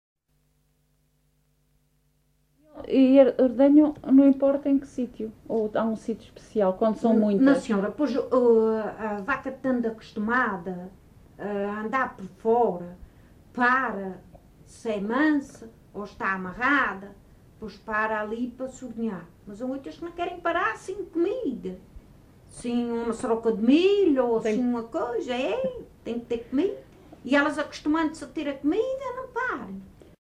LocalidadeFajãzinha (Lajes das Flores, Horta)